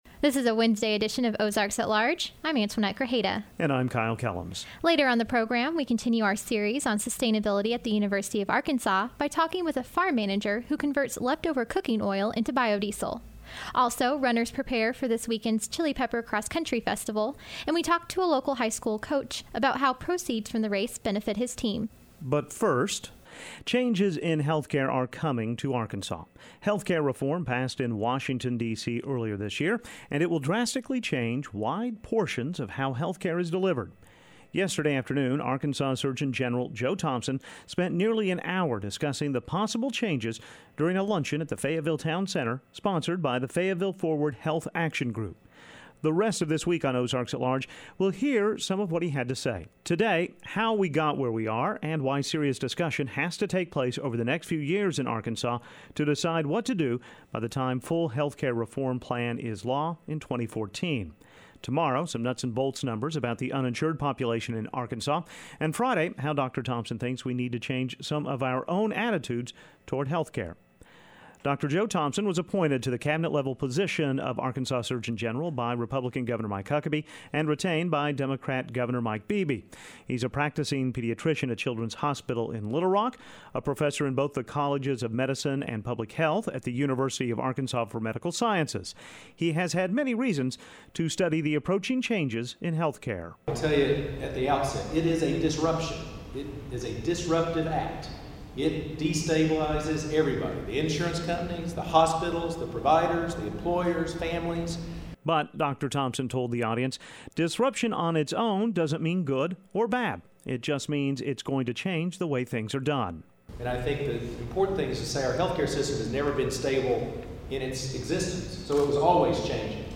Audio: weboct13.mp3 On this edition of Ozarks at Large, what coming healthcare reform might mean for Arkansas. Dr. Joe Thompson, the state's Surgeon General, discussed the new law during a luncheon yesterday in Fayetteville. On this edition, how we got to where we are with health care and the conversations Arkansans must have to determine what's next. Plus, biofuel on the University of Arkansas campus and more.